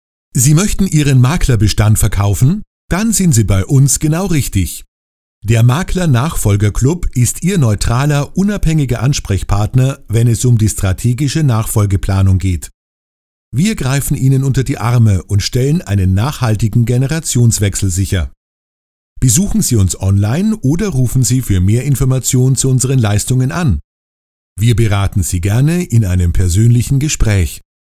Sprechprobe: Industrie (Muttersprache):
German voice artist for Radio, TV, Audio-Book, Commercials, E-Learning